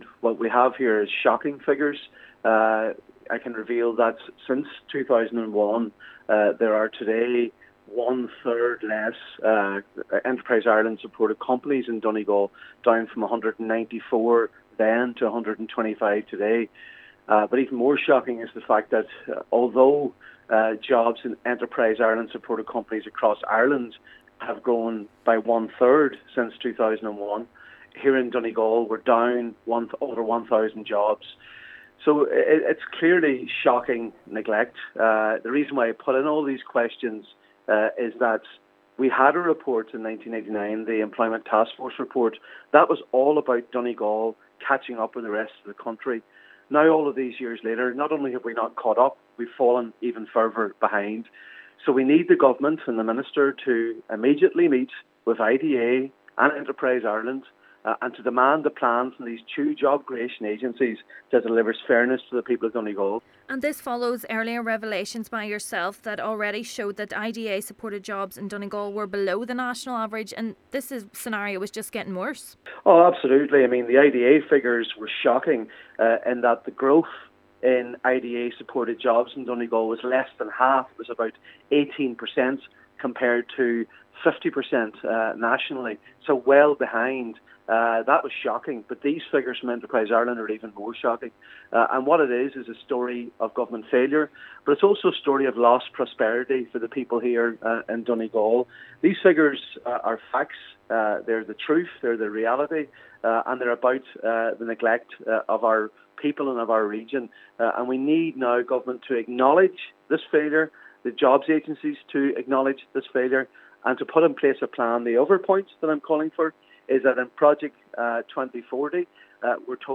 Donegal Senator Padraig MacLochlainn says what is needed is fairness for the people: